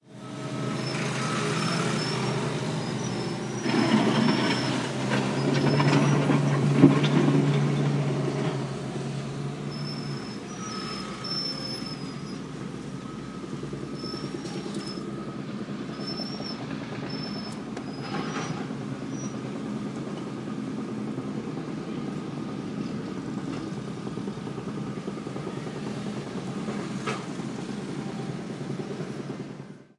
描述：Baja fidelidad：grabaciórealizadaen la Calle 18 con Carrera 50，víahaciala UniversidaddeNariño（Pasto）。 Enlahapeaciónseescuchan sonidos difusos que Corresponden avehículos，motocicletas y de la maquinariaqueestáaccionadaparalapavimentacióndelavía。 El audio fue grabado con un iPad y registradoeldía1de febrero de 2016。 低保真度：使用Carrera 50在Calle 18录制，前往纳里尼奥大学（帕斯托）。在录音中，可听到与车辆，摩托车和机械相对应的漫射声，这些声音用于铺设道路。音频采用iPad录制，并于2016年2月1日注册。